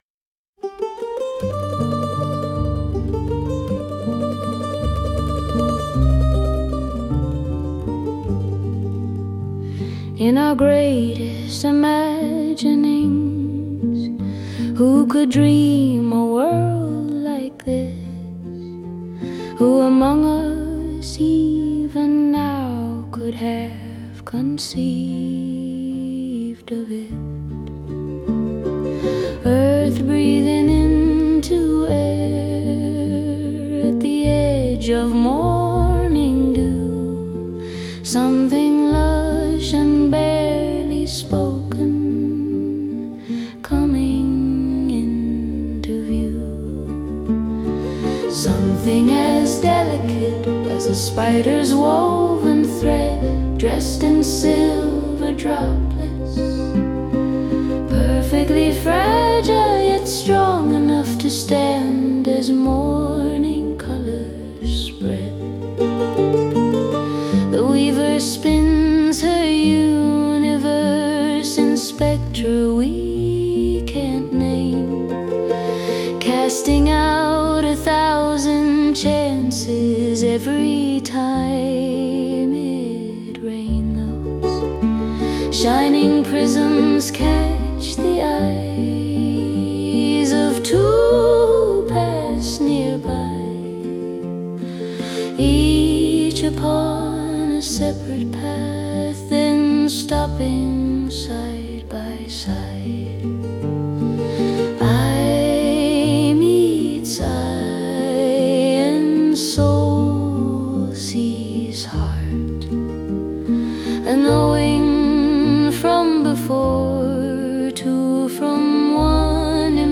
Witness · Wry Blues